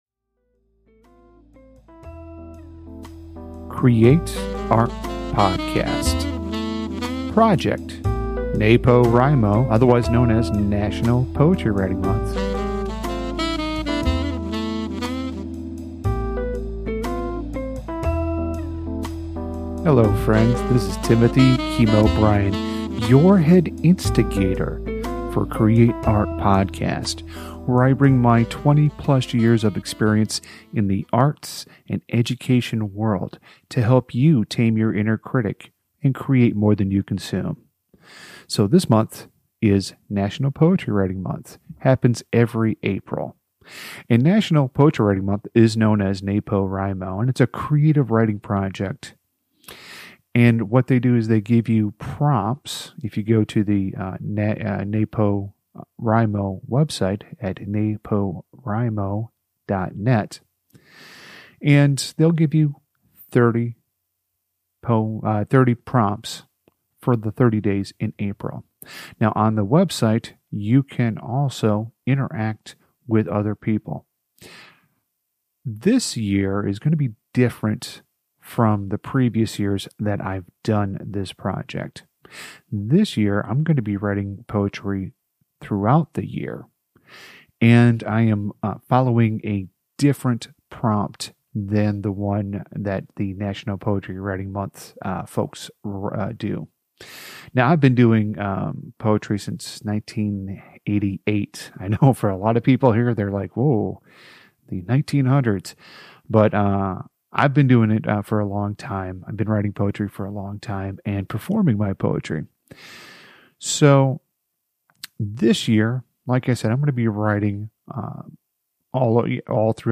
Some of the podcast episodes you will hear will be a live recording of me reading the poem to a live audience, other times I will be reading it in the comfort of my home studio.